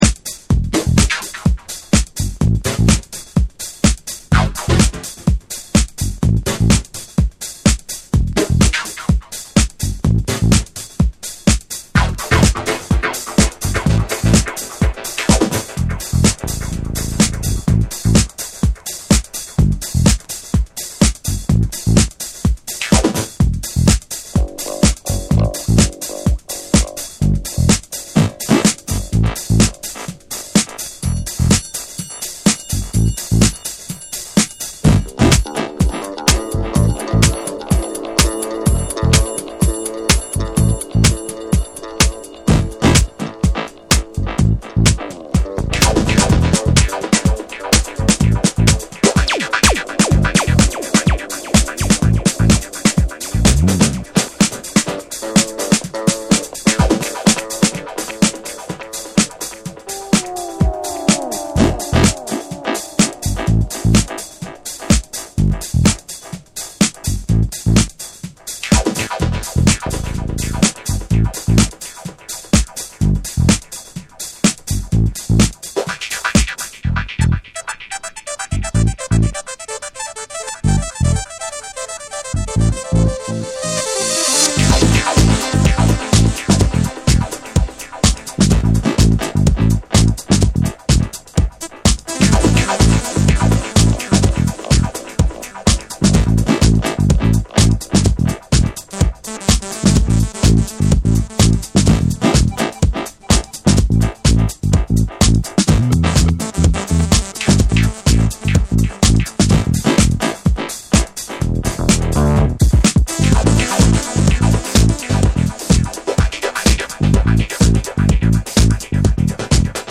TECHNO & HOUSE / DISCO DUB